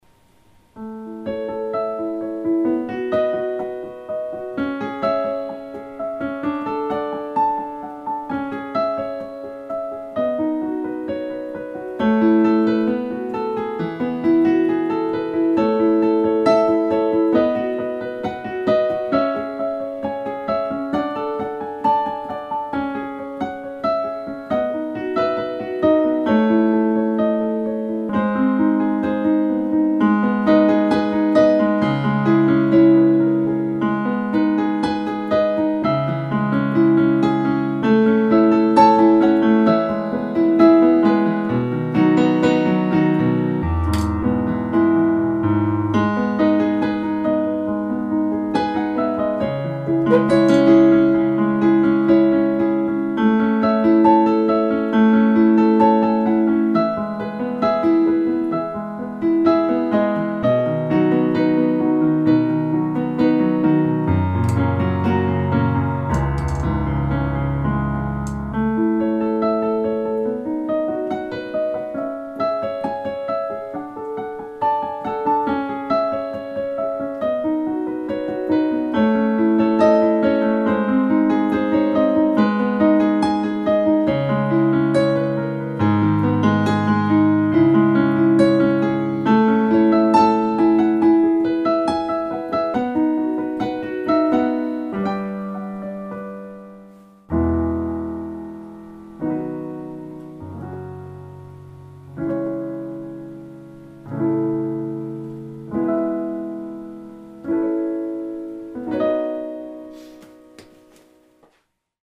Index of /music/pianoSketches